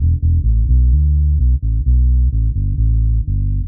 Index of /musicradar/dub-designer-samples/130bpm/Bass
DD_PBass_130_E.wav